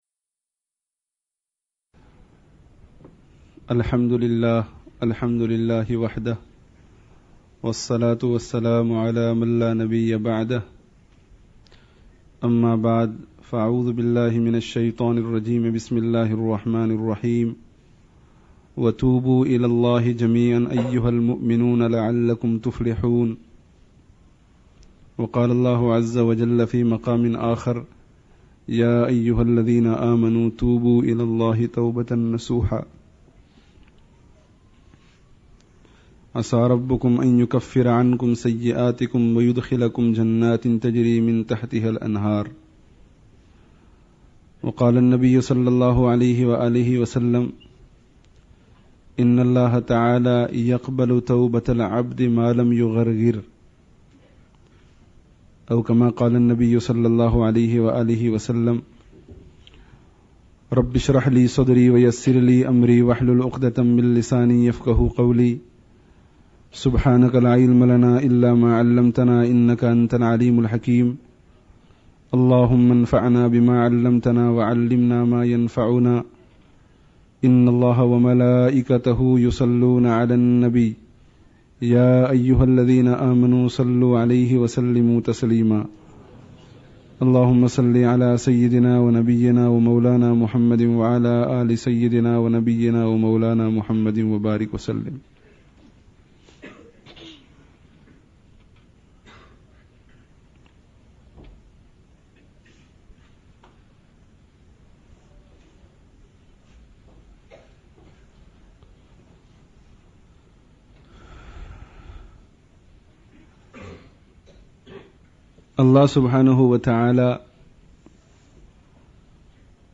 Shaytān's Ploy (8th Ramadhān 1417) (Masjid An Noor, Leicester 17/01/97)